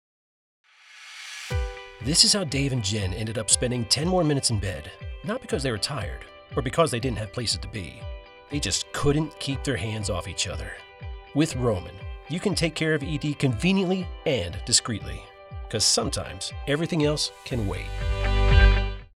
Calm, nurturing and exciting pharmacy spot